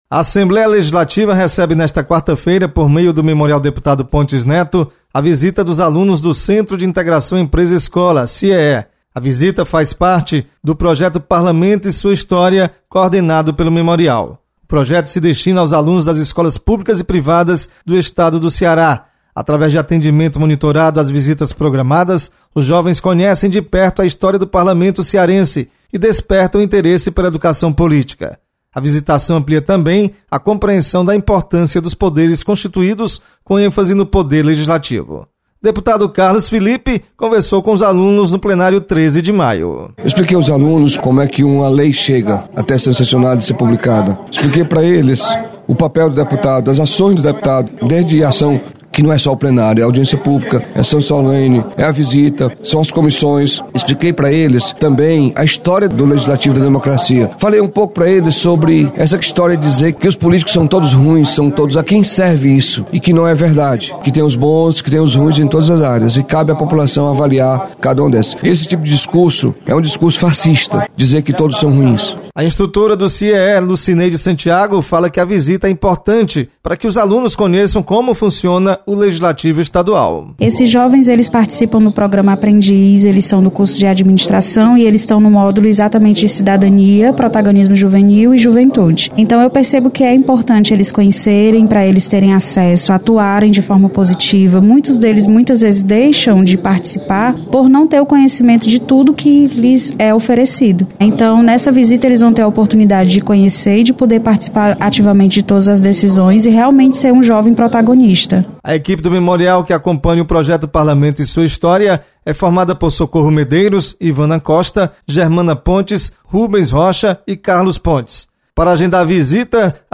Parlamento recebe estudantes. Repórter